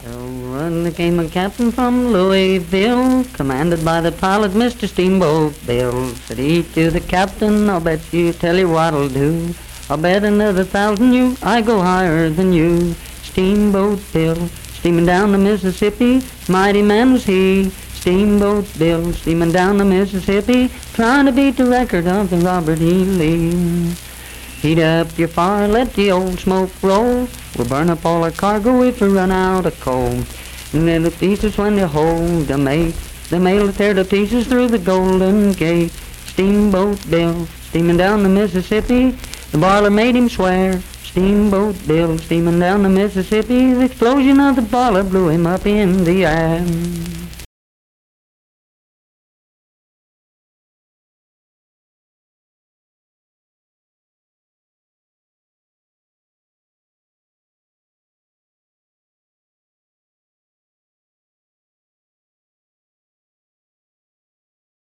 Unaccompanied vocal music performance
Voice (sung)
Wirt County (W. Va.)